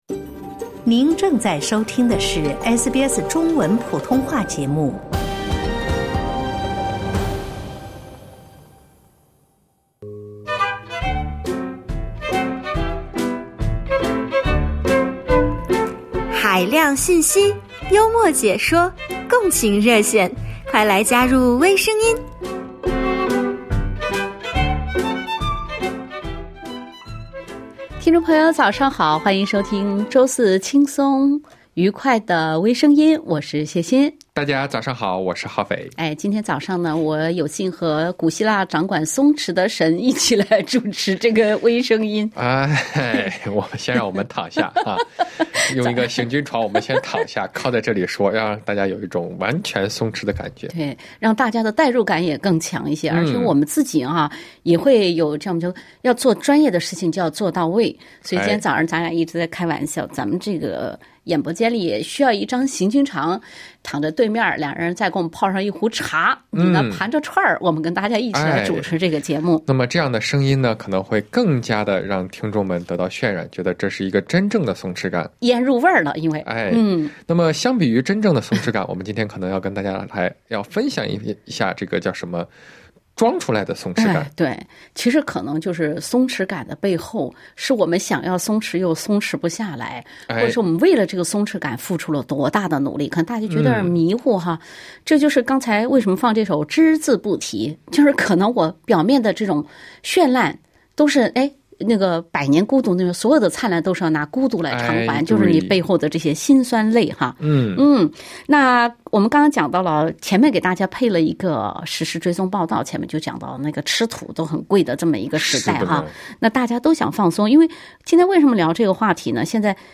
热心听众分享自己为了“显得松弛”而偷偷努力的事儿。